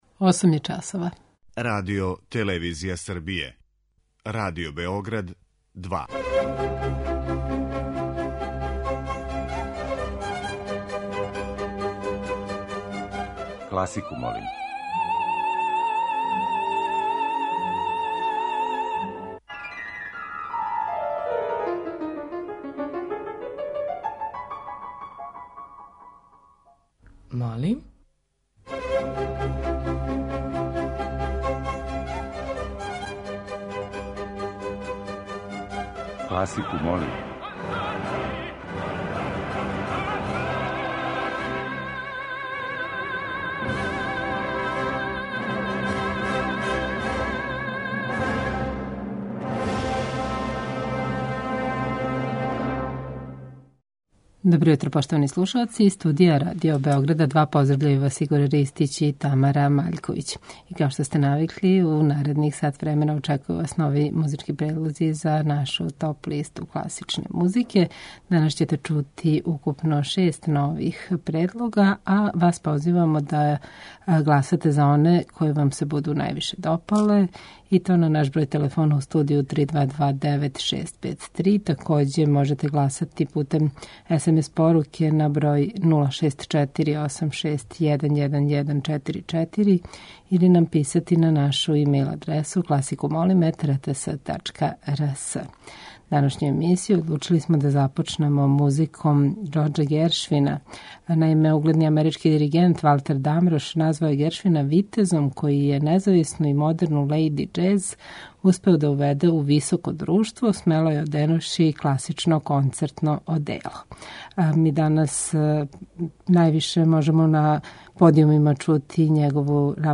Уживо вођена емисија, окренута широком кругу љубитеља музике, разноврсног је садржаја, који се огледа у пођеднакој заступљености свих музичких стилова, епоха и жанрова.